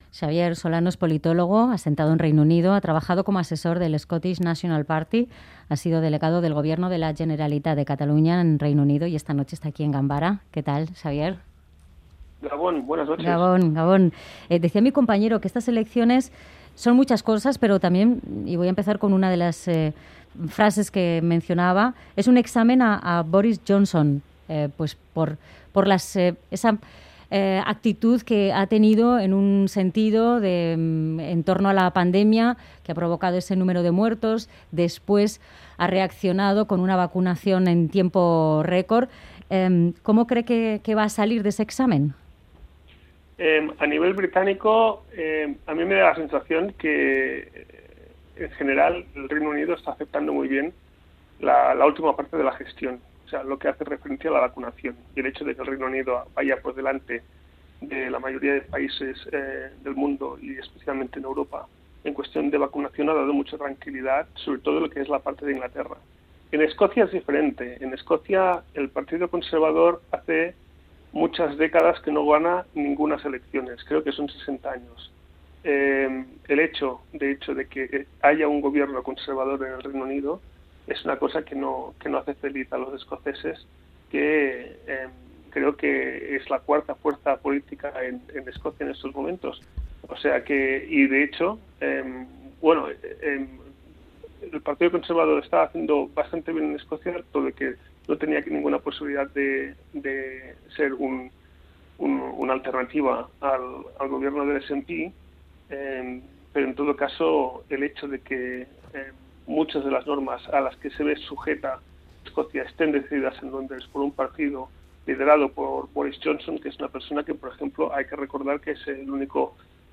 Elecciones en Escocia | Entrevista